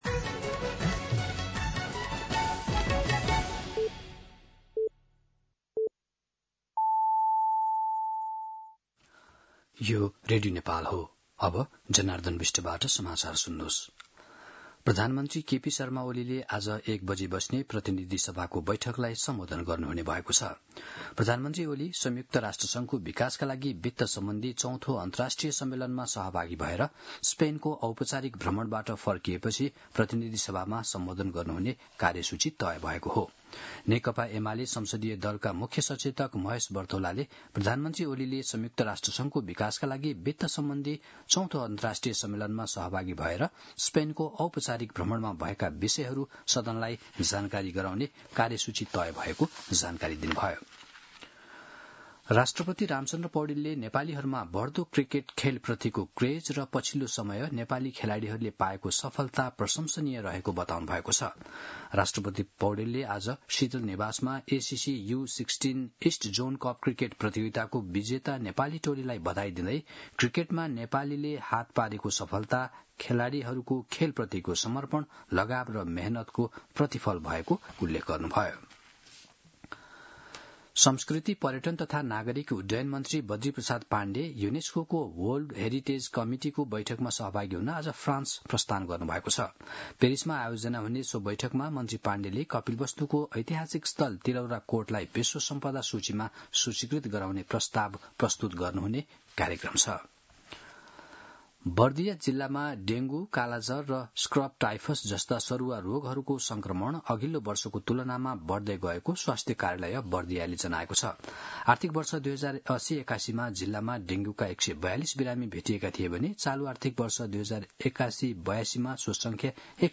मध्यान्ह १२ बजेको नेपाली समाचार : २३ असार , २०८२
12-pm-News-3-23.mp3